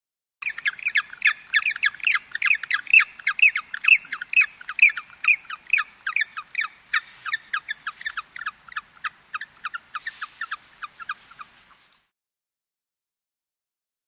Penguin Chirps. Penguin Vocalizations With A Faint Surf Wash In The Background. Medium Perspective.